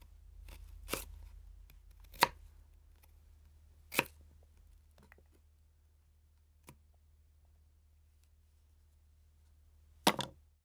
Kitchen Knife Chop Vegetables Sound
household
Kitchen Knife Chop Vegetables